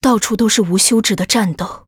文件 文件历史 文件用途 全域文件用途 Yoshua_amb_03.ogg （Ogg Vorbis声音文件，长度1.9秒，110 kbps，文件大小：25 KB） 文件说明 源地址:游戏语音解包 文件历史 点击某个日期/时间查看对应时刻的文件。 日期/时间 缩略图 大小 用户 备注 当前 2019年1月20日 (日) 04:26 1.9秒 （25 KB） 地下城与勇士  （ 留言 | 贡献 ） 分类:寒冰之休亚 分类:地下城与勇士 源地址:游戏语音解包 您不可以覆盖此文件。